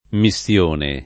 [ mi SSL1 ne ]